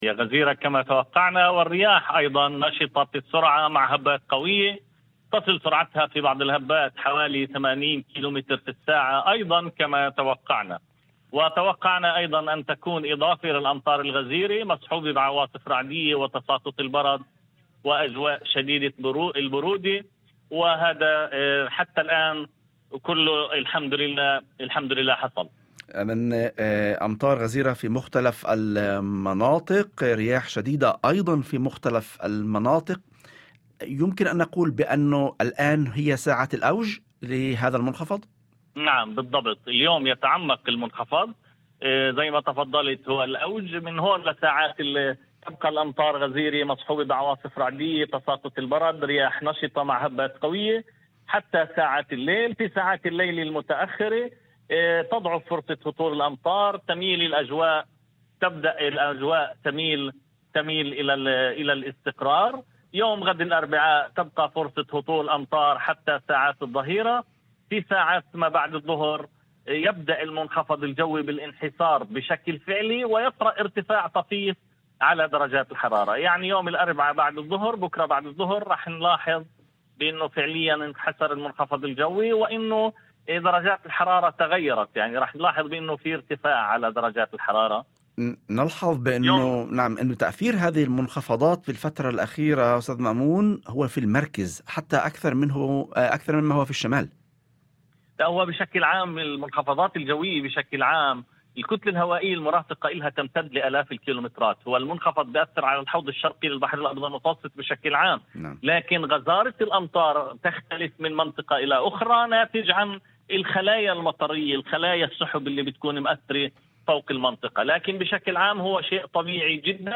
وأضاف في مداخلة هاتفية ضمن برنامج "يوم جديد"، على إذاعة الشمس، أن فرص هطول الأمطار تبدأ بالتراجع خلال ساعات الليل المتأخرة، على أن تميل الأجواء تدريجيًا إلى الاستقرار، مبينًا أن يوم غد الأربعاء سيشهد بقاء فرص الأمطار حتى ساعات الظهيرة، قبل أن يبدأ المنخفض الجوي بالانحسار الفعلي خلال ساعات ما بعد الظهر، ويترافق ذلك مع ارتفاع طفيف على درجات الحرارة.